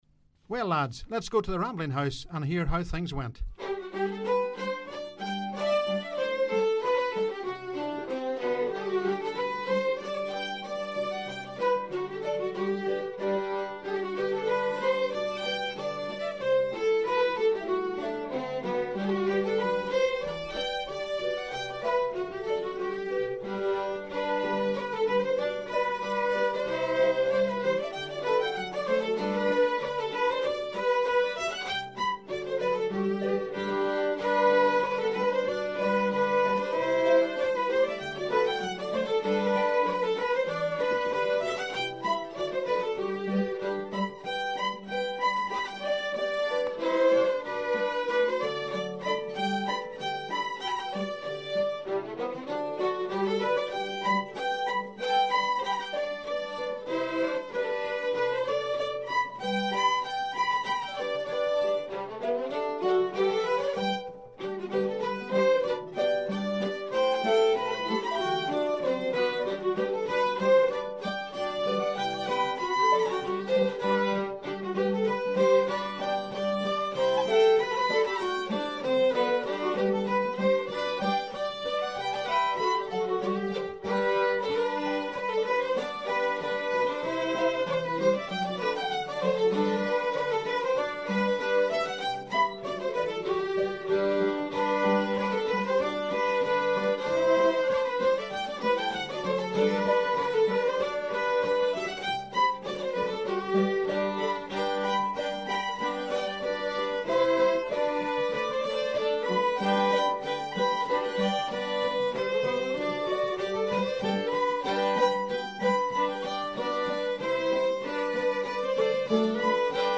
TBN - musical introduction
his band playing a tune